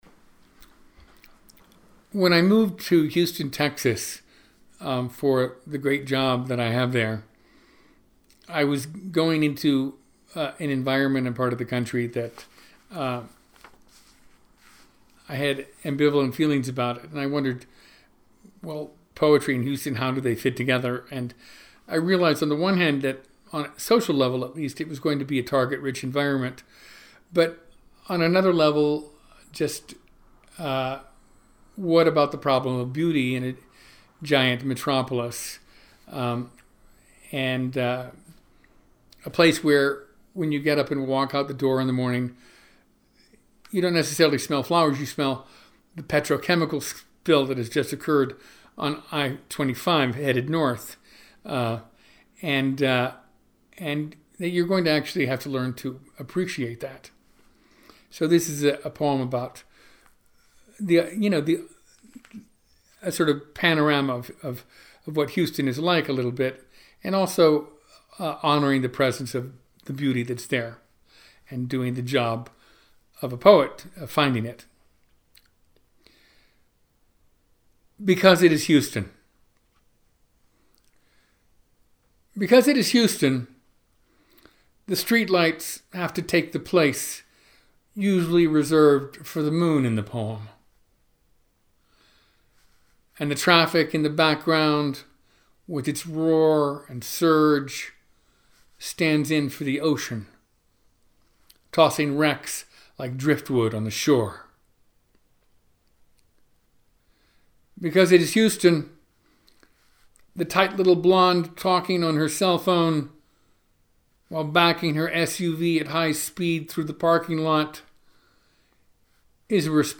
In the summer of 2018 Tony recorded himself reading twenty of those poems.